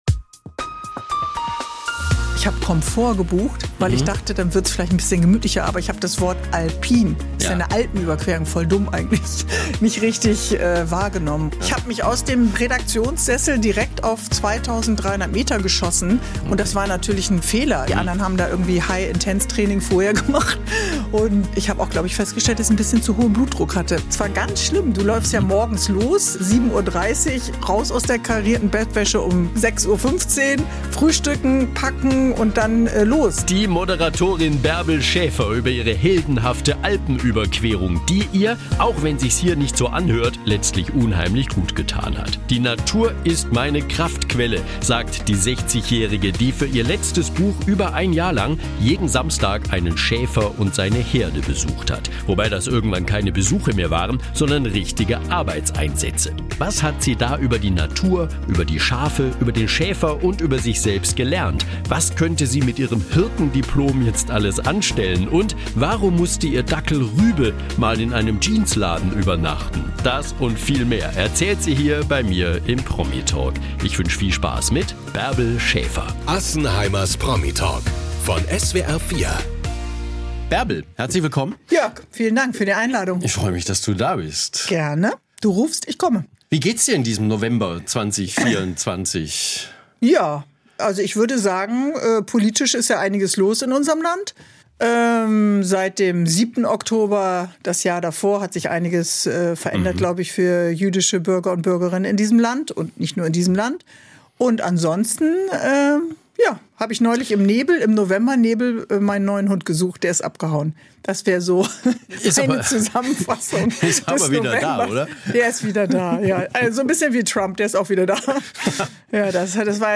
Interview
Studiogäste